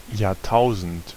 Ääntäminen
Ääntäminen Tuntematon aksentti: IPA: /jaːɐˈtaʊ̯zn̩t/ Haettu sana löytyi näillä lähdekielillä: saksa Käännös 1. milenio {m} Artikkeli: das .